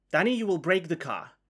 crash3.ogg